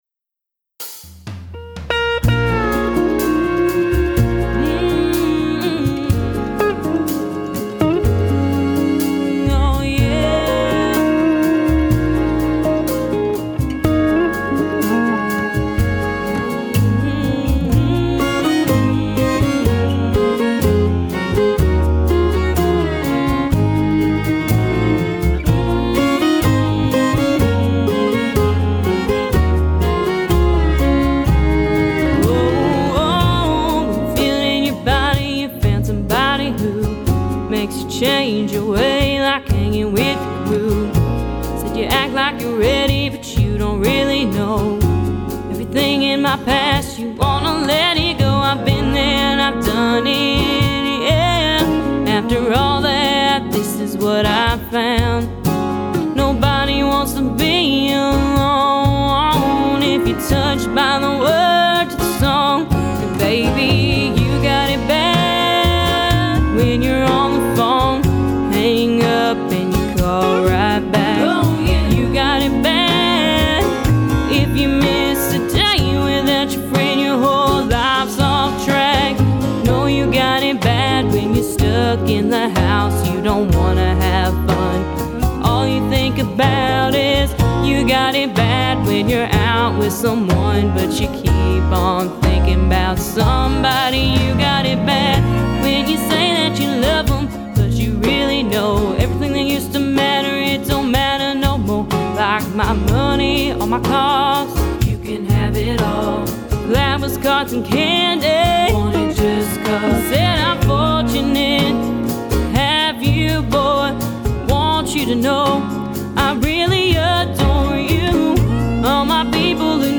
adds a country twist to it
turned country